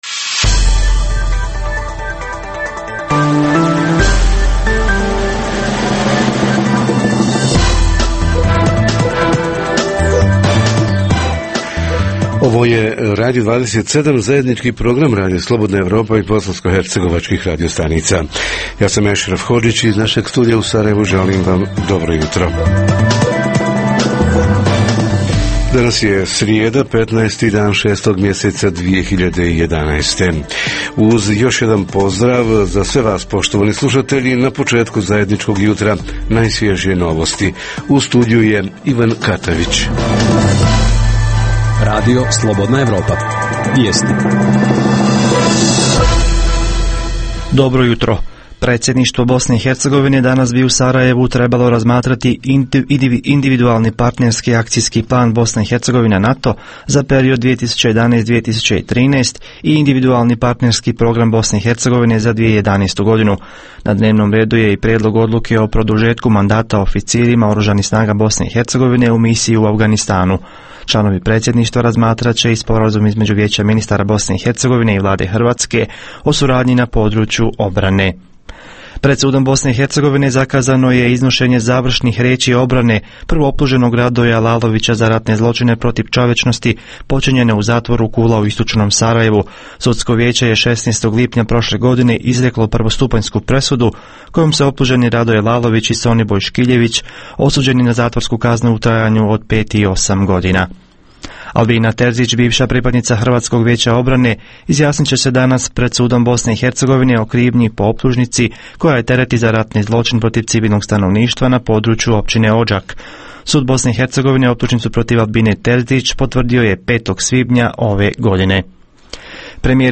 Kampovi, radionice, ljetovanja, festivali i slično – kako ovog ljeta očuvati i unaprijediti aktivizam mladih? Reporteri iz cijele BiH javljaju o najaktuelnijim događajima u njihovim sredinama.